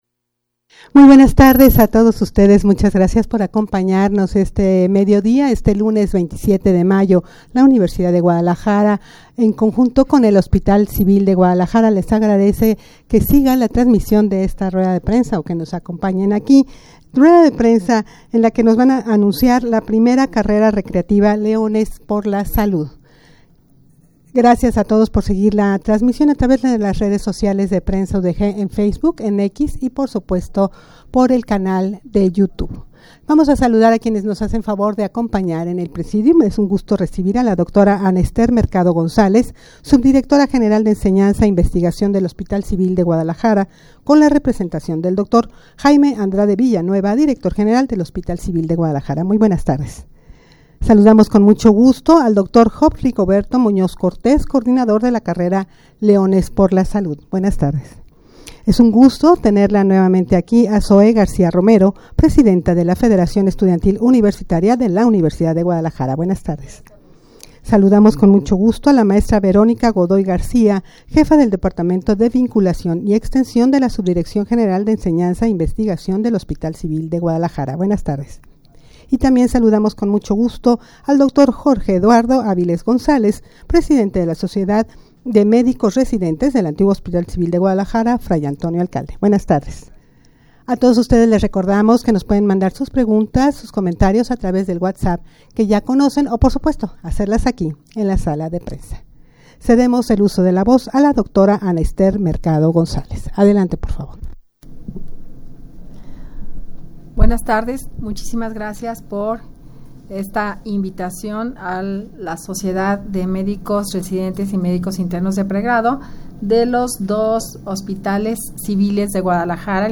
Audio de la Rueda de Prensa
rueda-de-prensa-en-la-que-se-anunciara-la-primera-carrera-recreativa-leones-por-la-salud.mp3